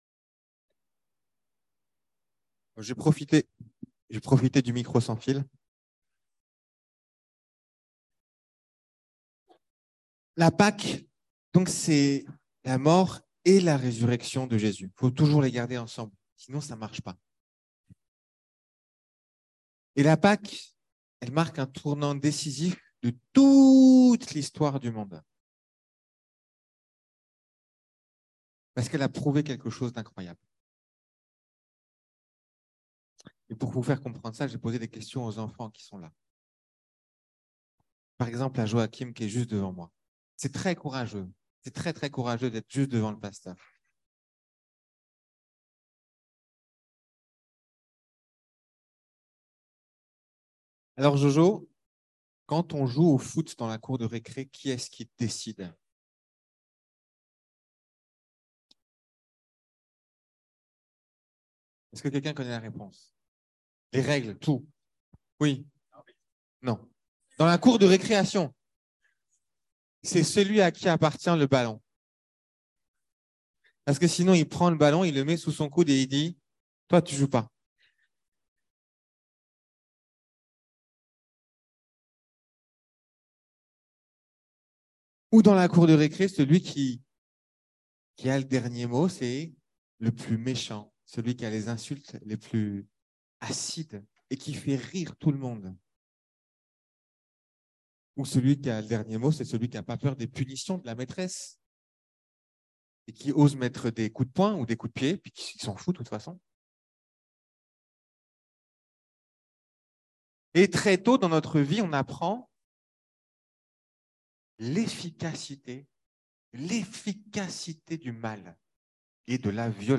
Exhortation